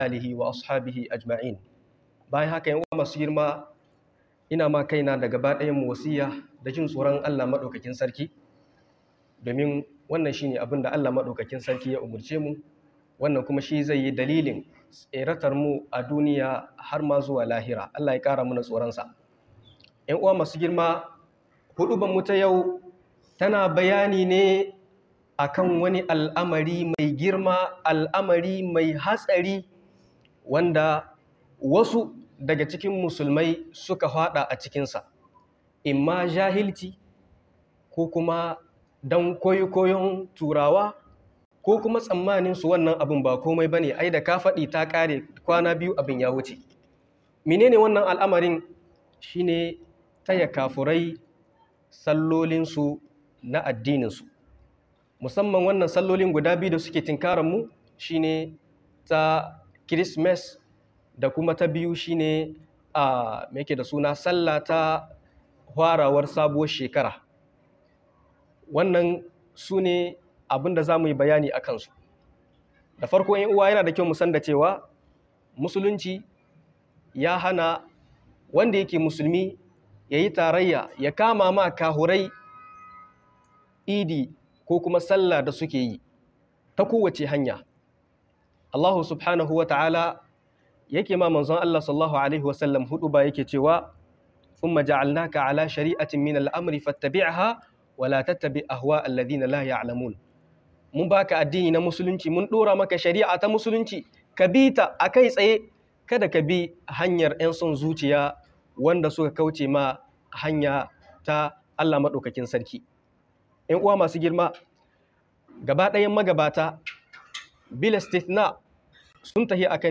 Jan kunnay gameda sallar kafiray - MUHADARA